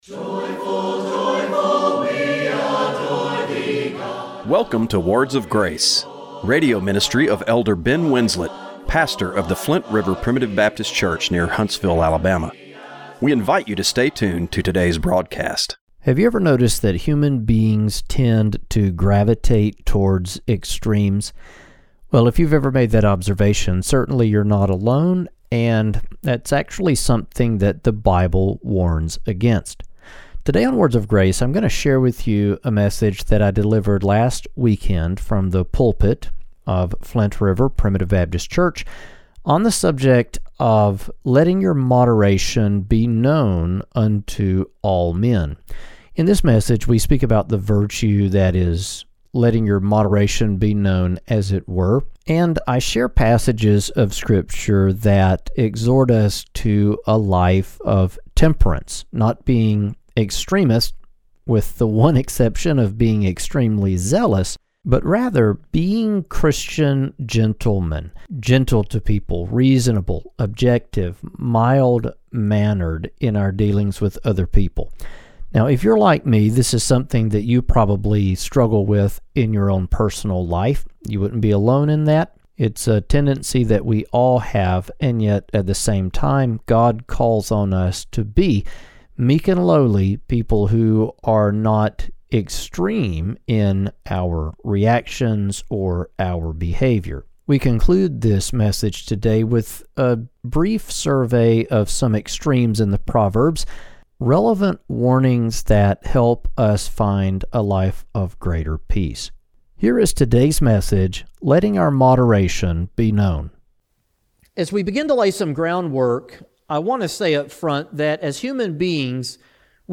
On this weekend’s edition of Words of Grace, we share a message from the pulpit of River Primitive Baptist Church entitled Biblical Wisdom on Extremes. In this sermon, we explore how Scripture teaches us to avoid the natural human tendency toward extremes — whether in our thinking, our reactions, or our daily living.
Radio broadcast for April 13, 2025.